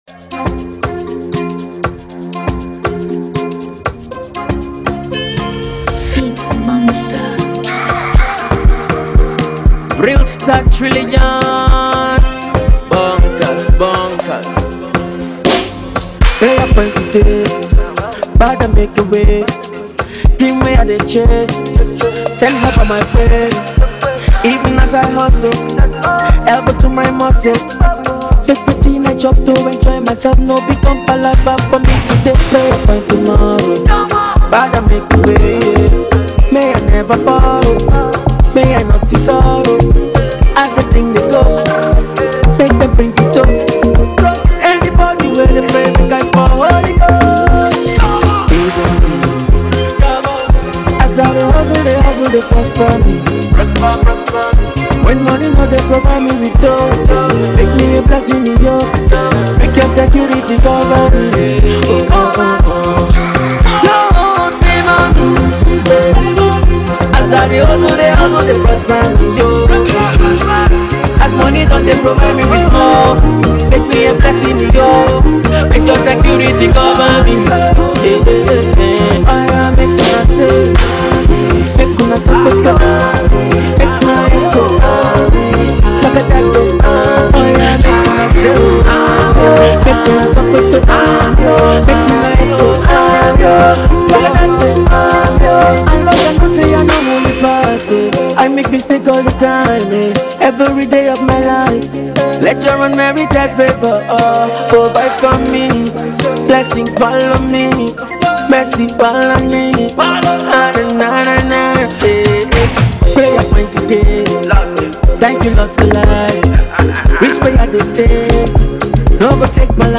Emerging afrobeats act
The Pop number